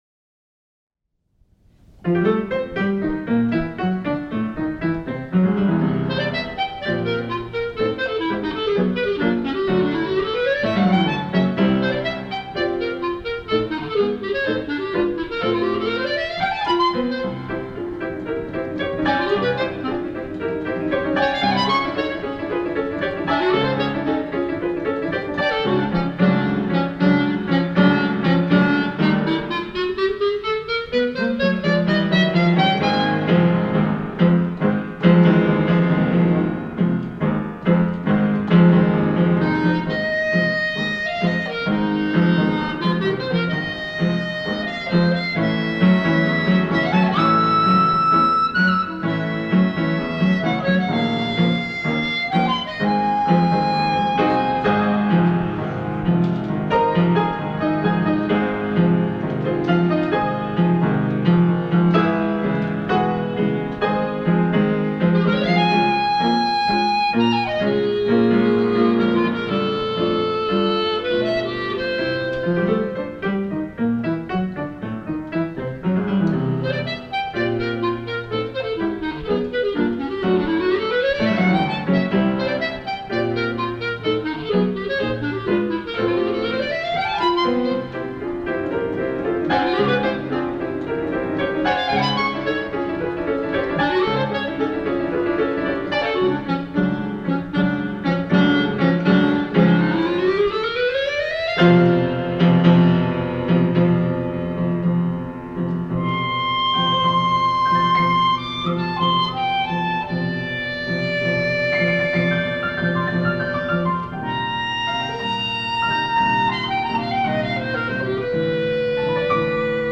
for clarinet and piano